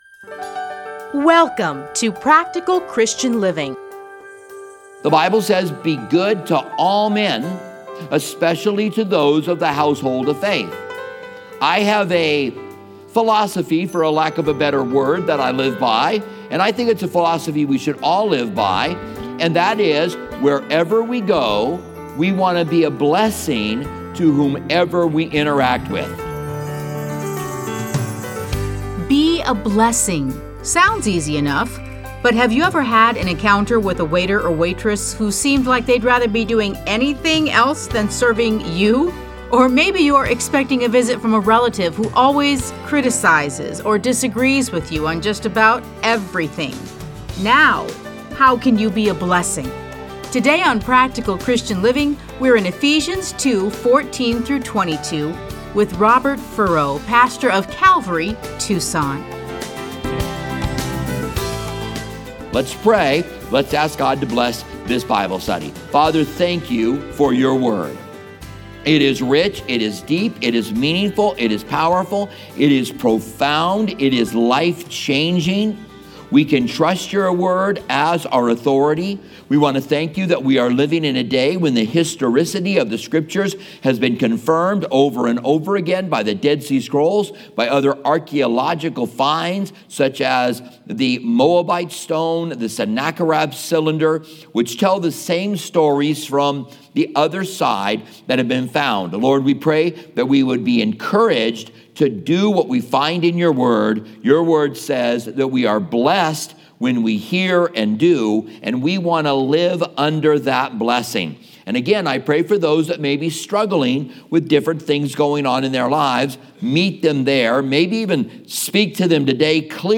Listen to a teaching from Ephesians 2:14-22.